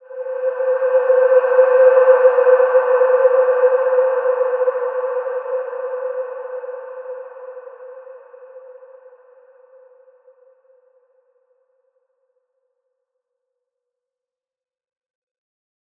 Large-Space-C5-f.wav